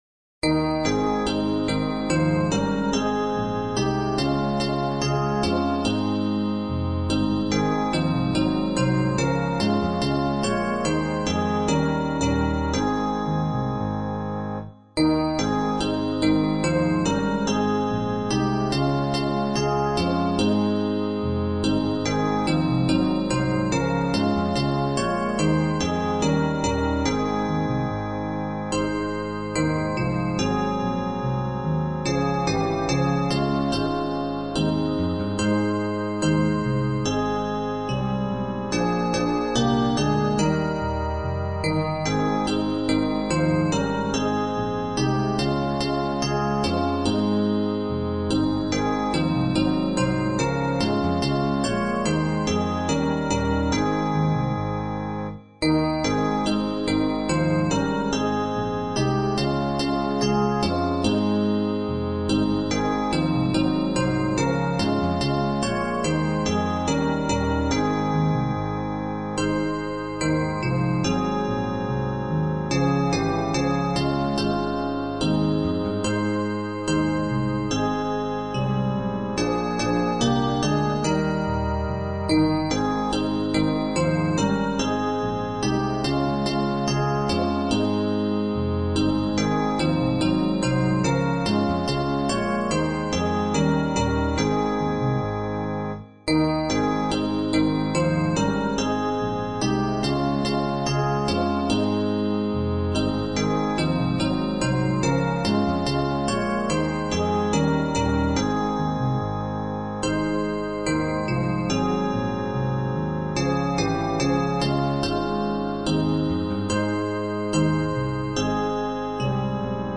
这首欢快优美的诗歌向我们指出了幸福人生的真谛，那就是爱。